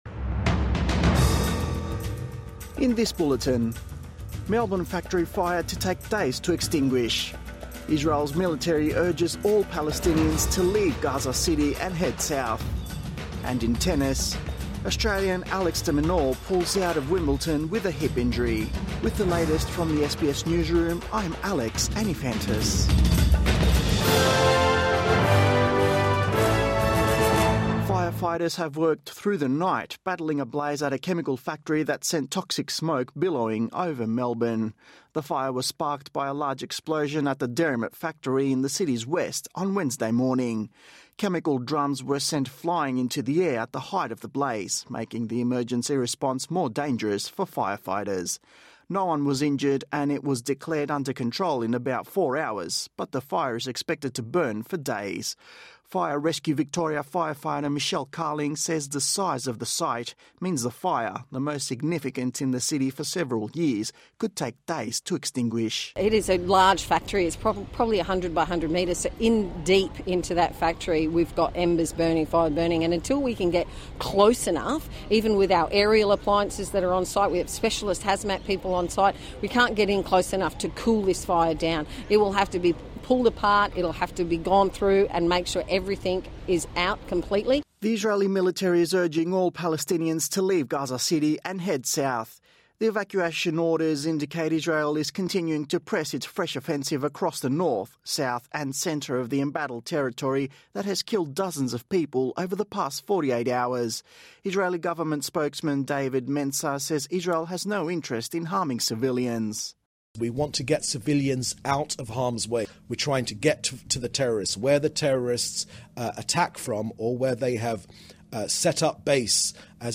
Morning News Bulletin 11 July 2024